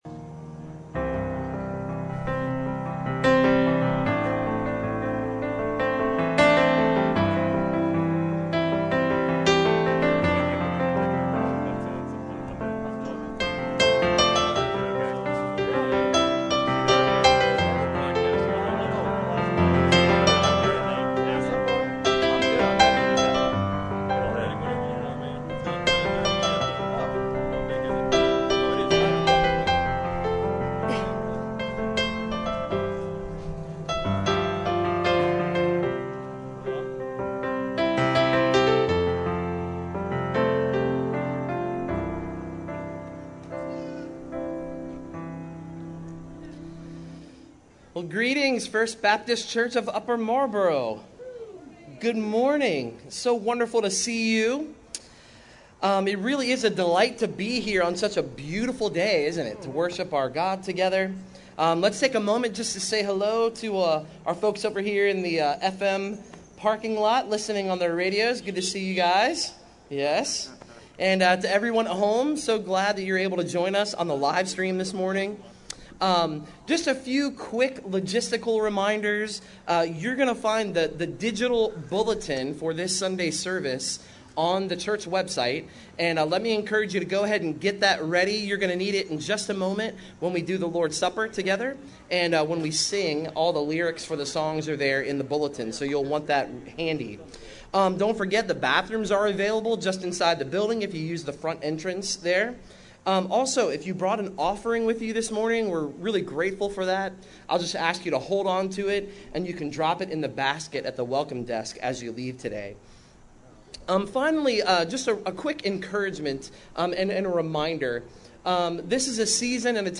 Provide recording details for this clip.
SERVICE-Sin-of-Partiality_.mp3